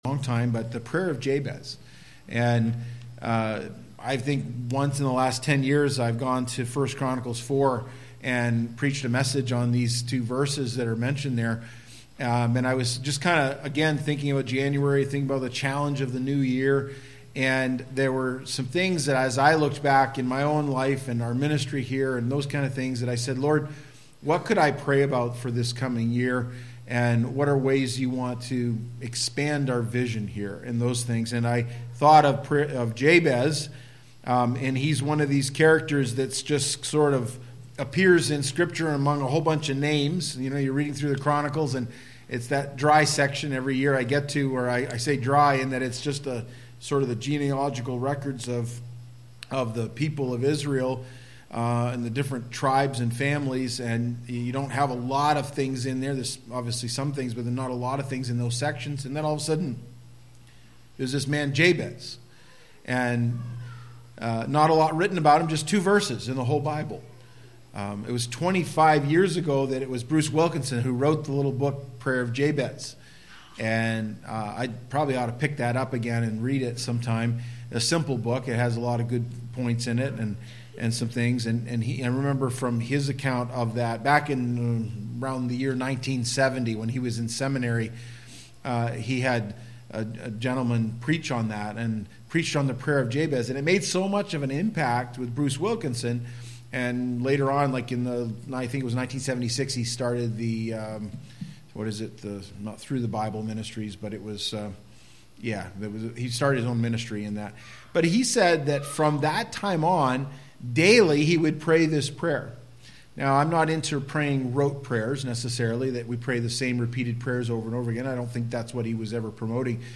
Sermons by Madawaska Gospel Church